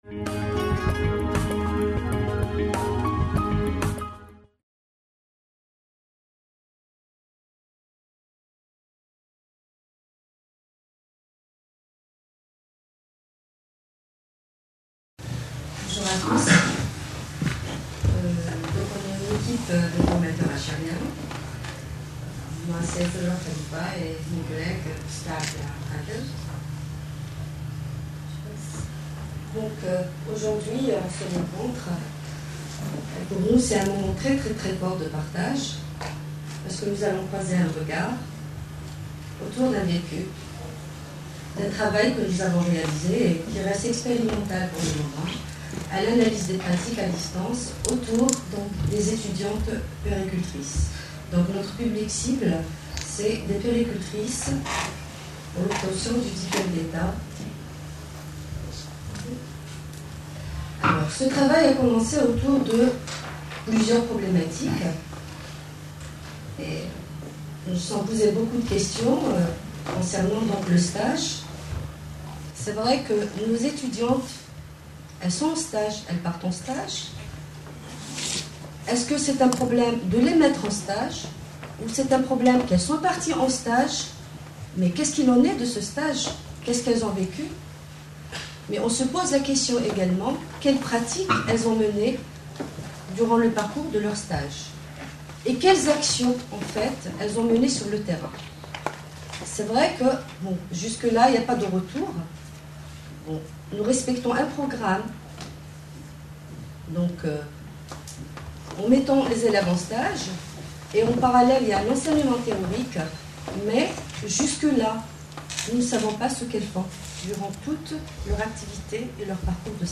Conférence enregistrée lors du congrès international FORMATIC PARIS 2011.